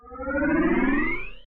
machine_power_on.ogg